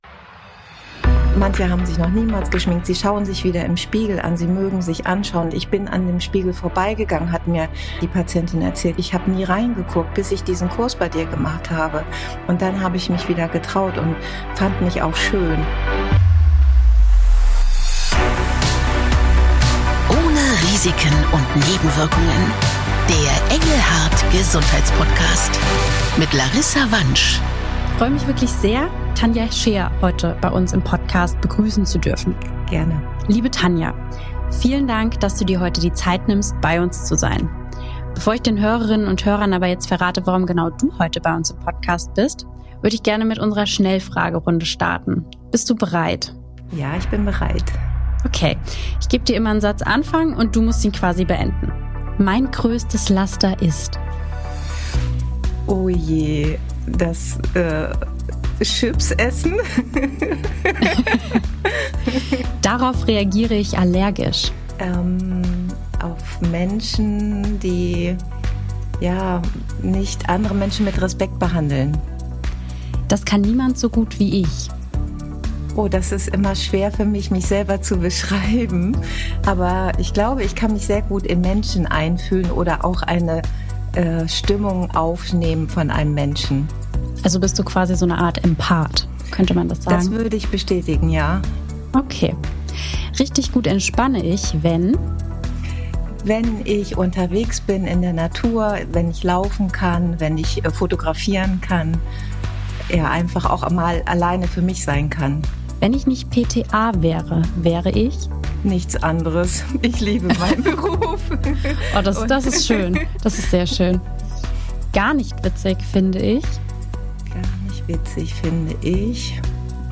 Ein besonderer Fokus liegt auf der Hautpflege, denn Chemotherapie und andere Belastungen setzen der Haut oft stark zu. Im Gespräch